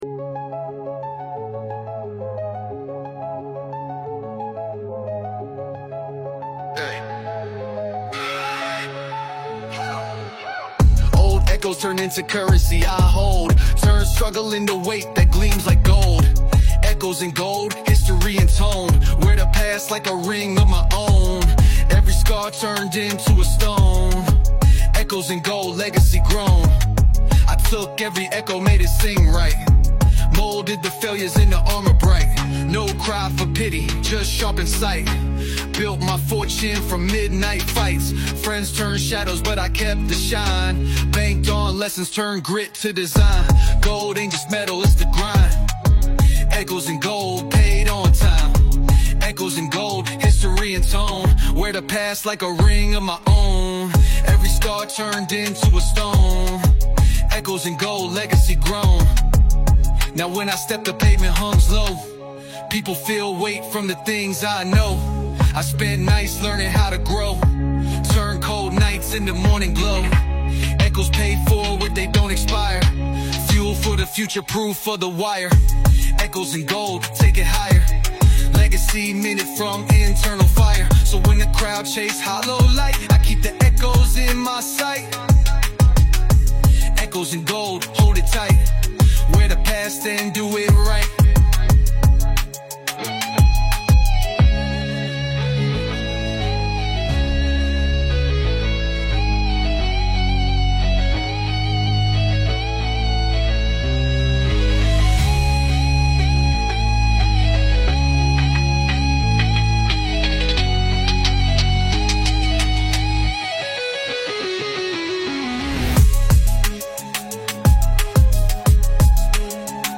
thoughtful, powerful bars
smooth, melodic beat